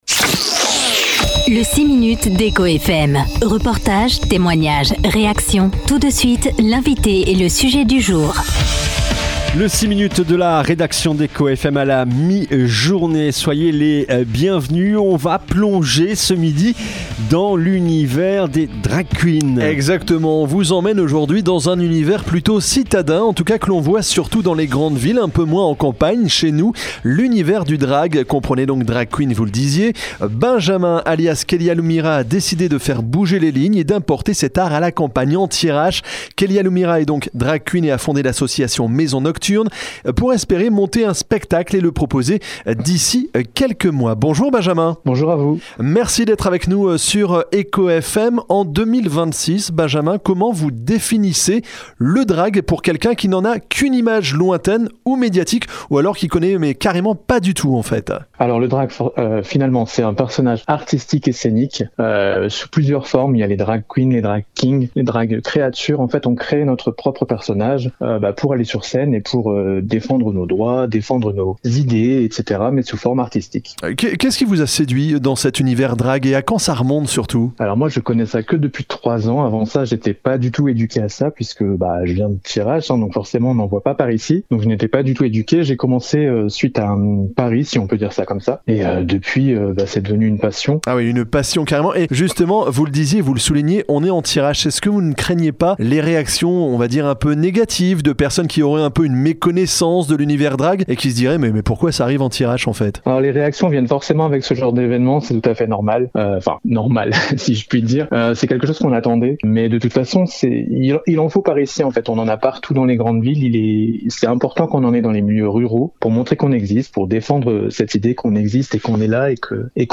invité d’Écho FM ce mardi 3 février.